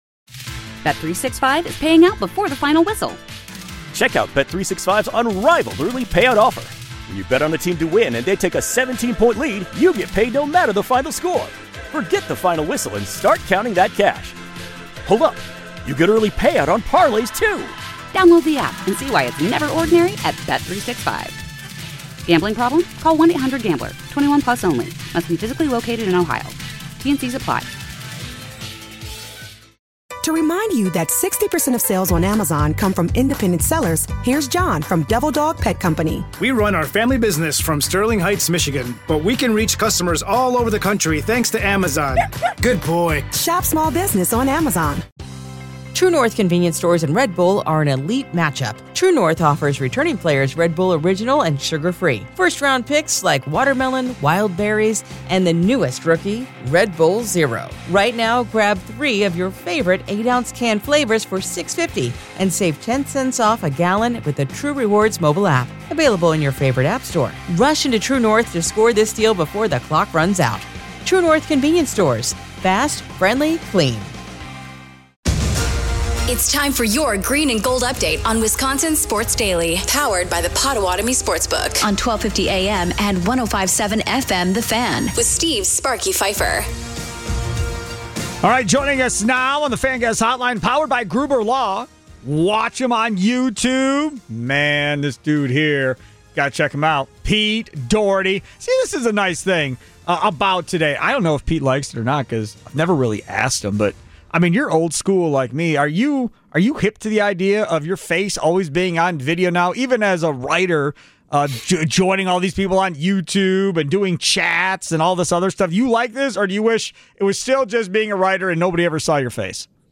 Also hear players coaches and our Football insiders.